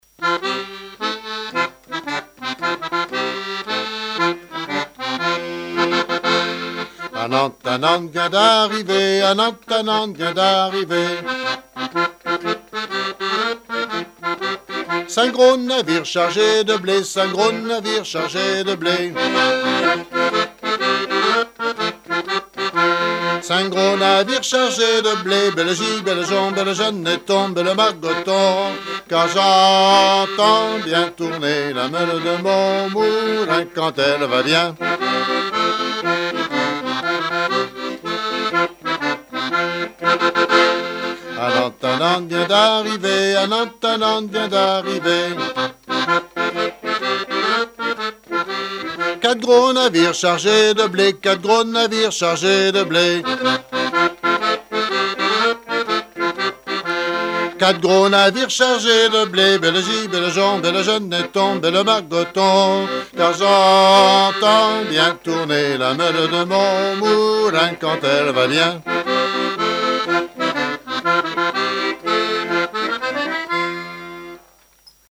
danse de la meule
Saint-Christophe-du-Ligneron
Pièce musicale inédite